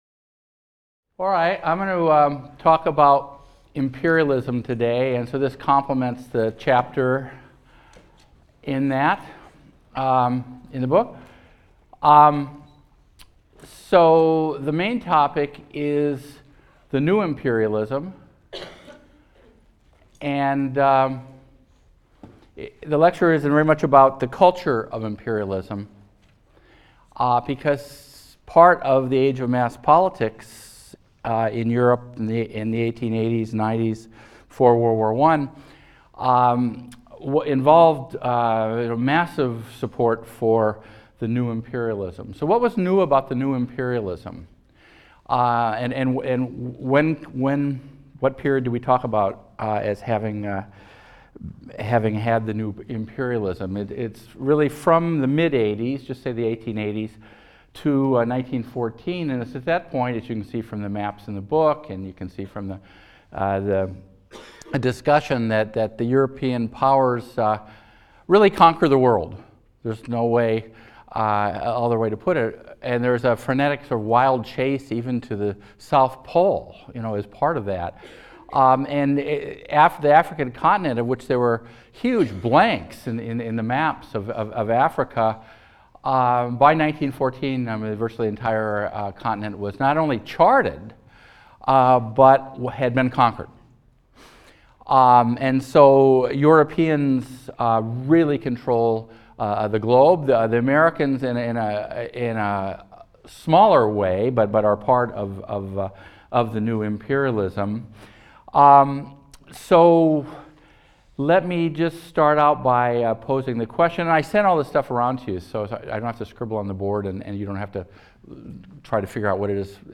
HIST 202 - Lecture 15 - Imperialists and Boy Scouts | Open Yale Courses